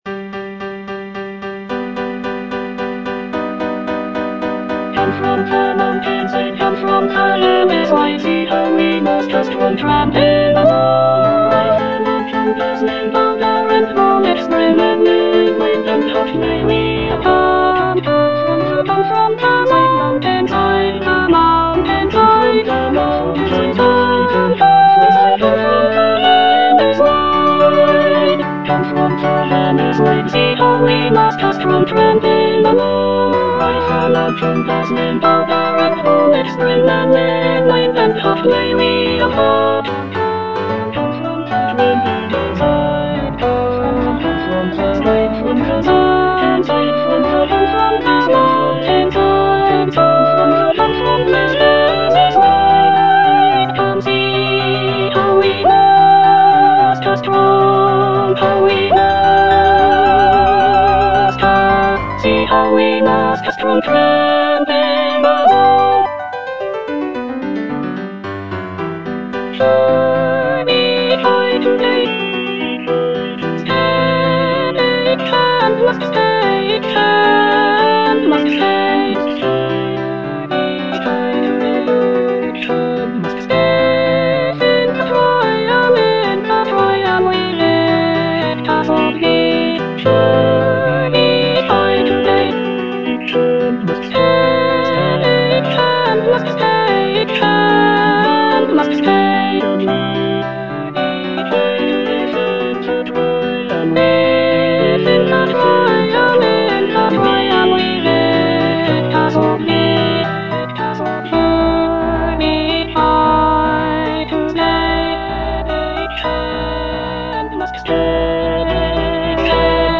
E. ELGAR - FROM THE BAVARIAN HIGHLANDS The marksmen (soprano II) (Emphasised voice and other voices) Ads stop: auto-stop